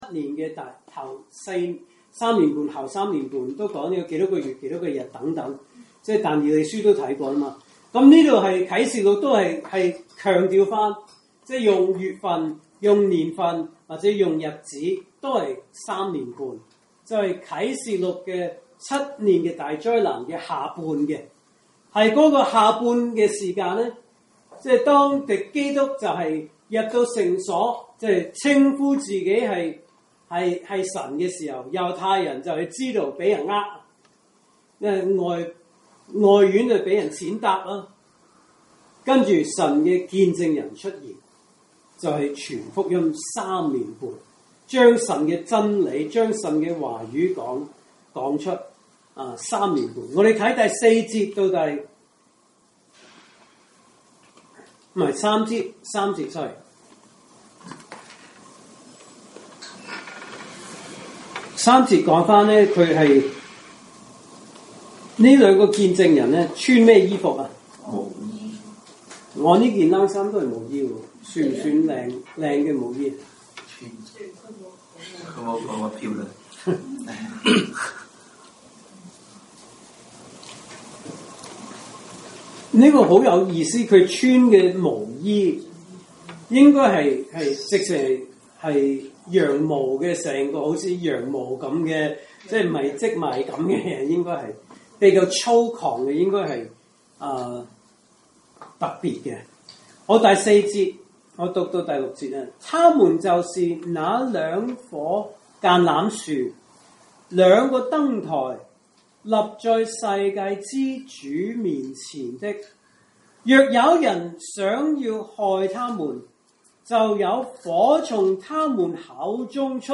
來自講道系列 "查經班：啟示錄"